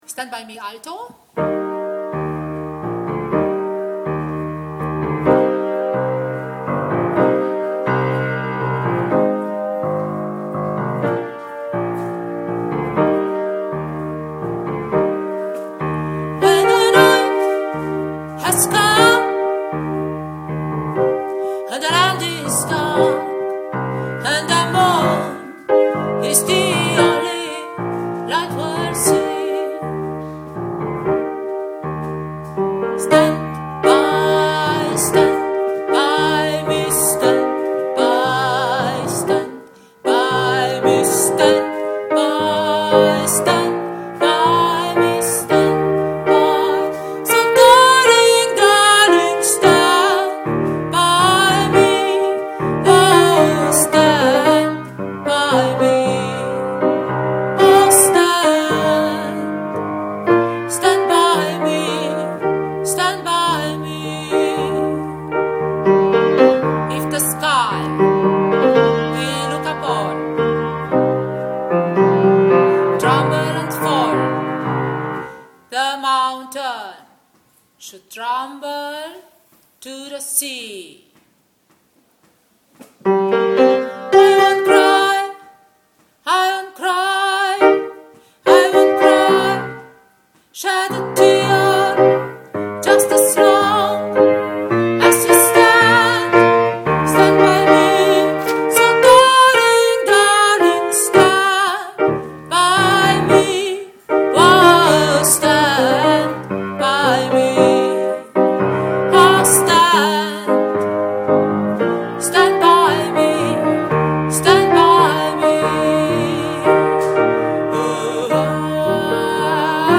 Stand by me – Alto